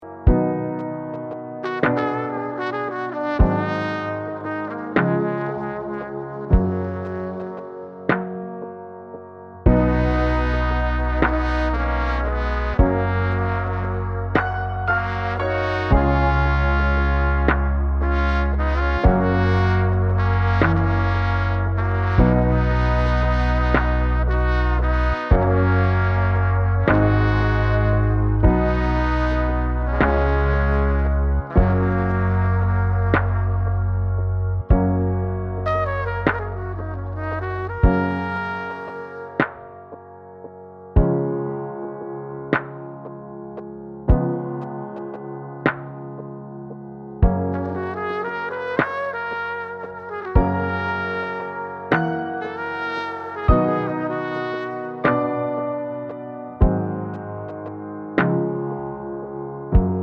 Professional Pop (2010s) Backing Tracks.